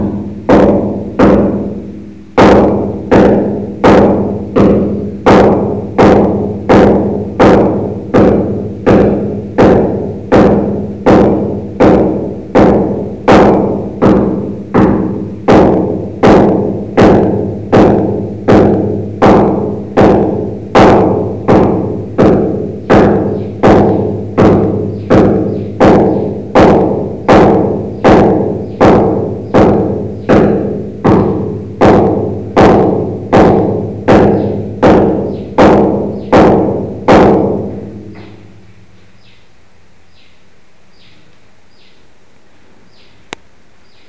thumping on the floor above
thumping-on-the-floor-above.mp3